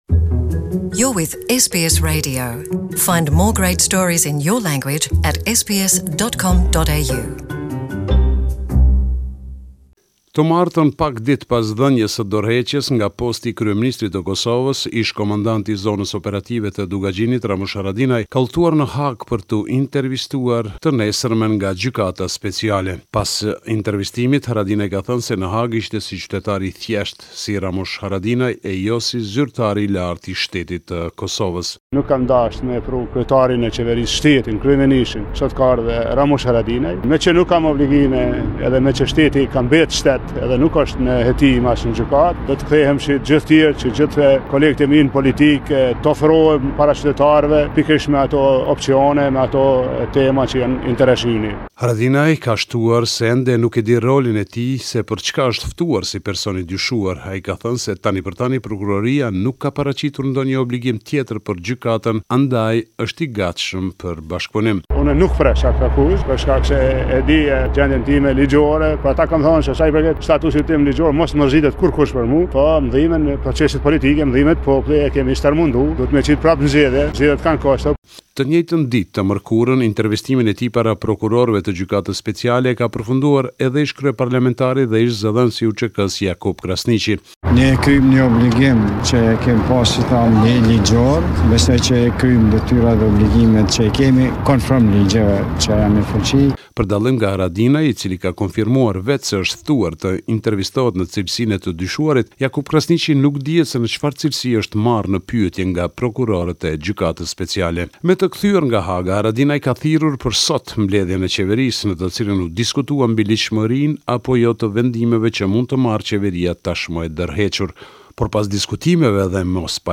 This is a report summarising the latest developments in news and current affairs in Kosova